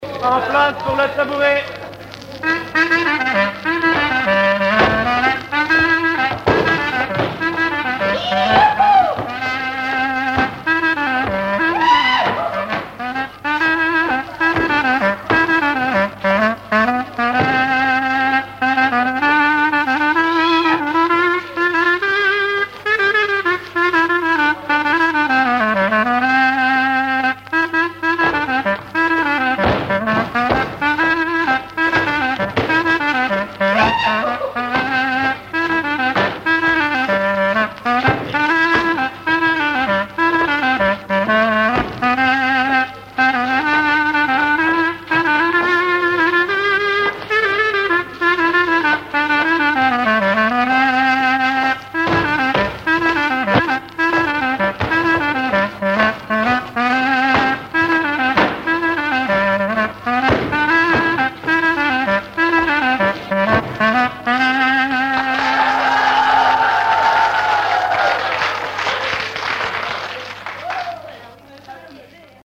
danse-jeu : tabouret, chaise, tréteau
Genre brève
Pièce musicale inédite